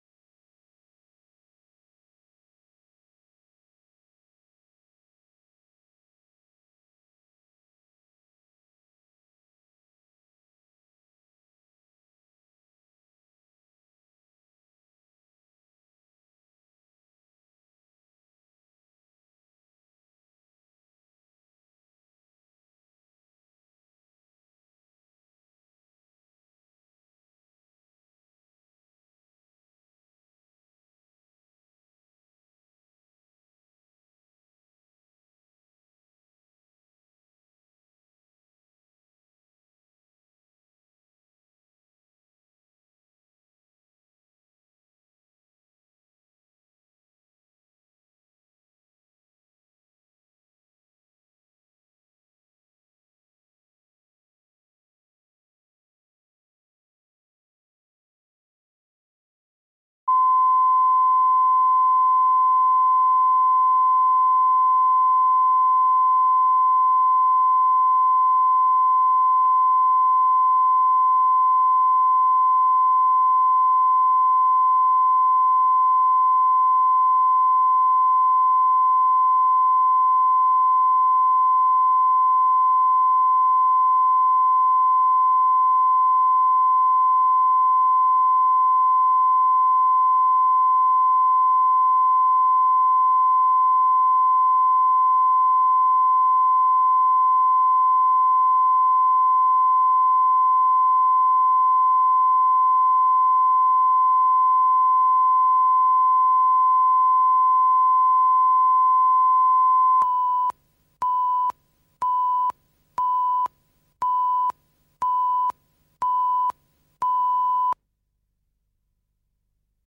Аудиокнига Жена начальника